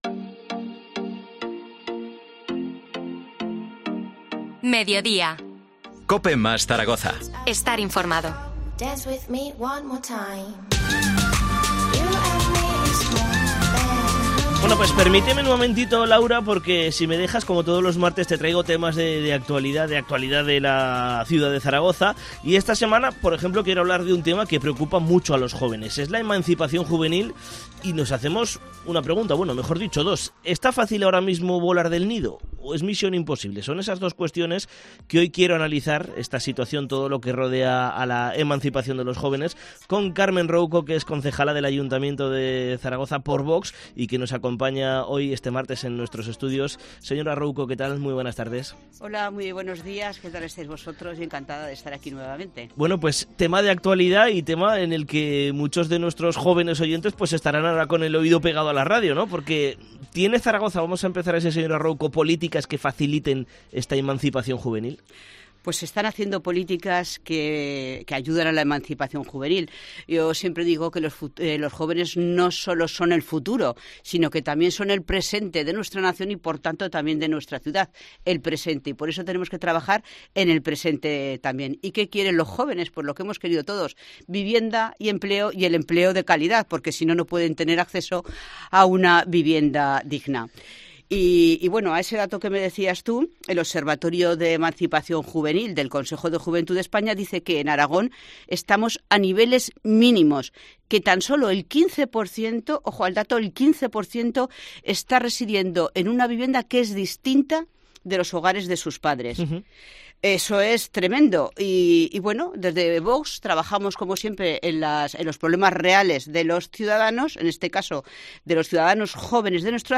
Entrevista a Carmen Rouco, concejala de VOX en el Ayuntamiento de Zaragoza sobre la emancipación juvenil